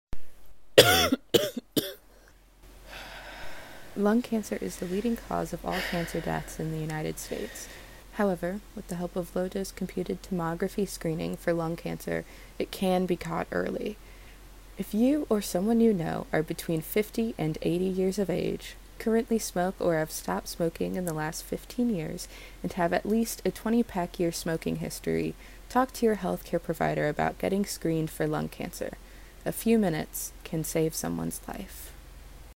Radio PSAs